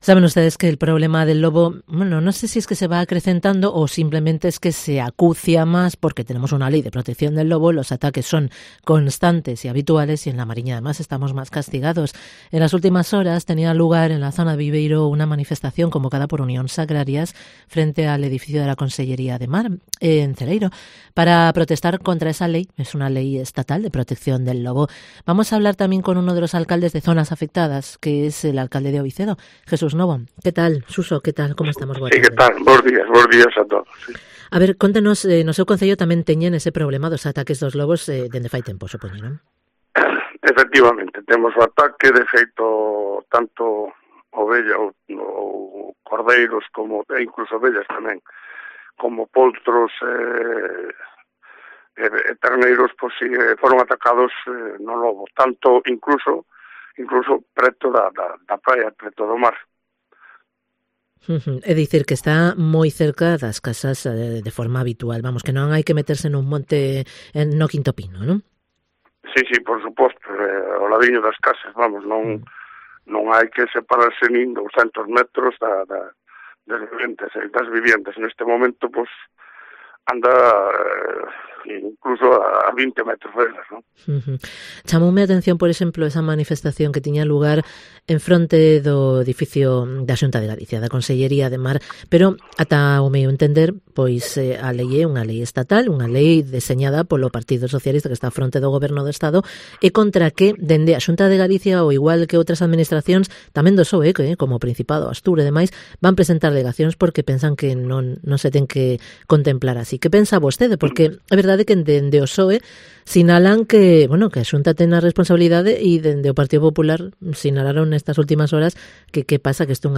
Entrevista Jesús Novo, alcalde de O Vicedo